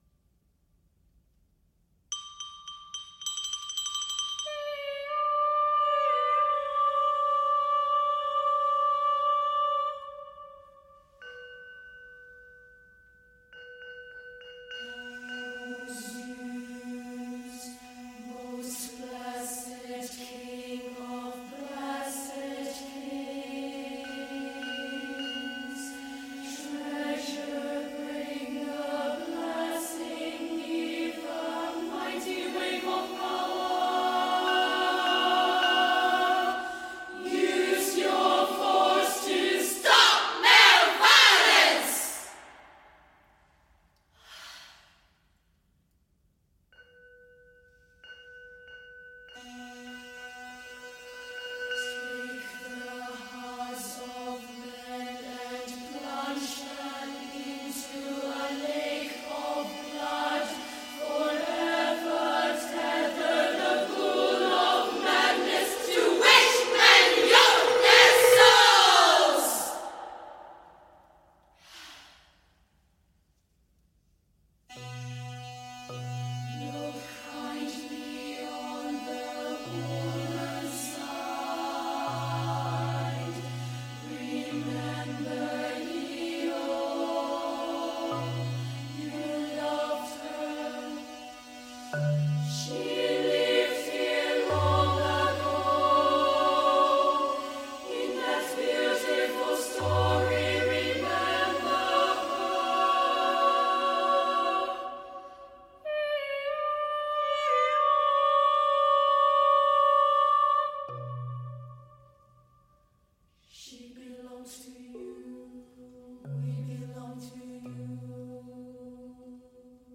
These are sample recordings from the 2016 Production of the play done at the Lyceum Theater in Edinburgh, Scotland.